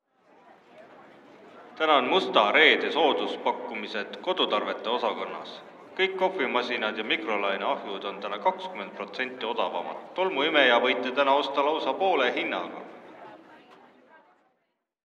Kuulamistekstid eesti keele töövihikule „Suhtleme Eestis A2.2“.
17. Kuulamistestid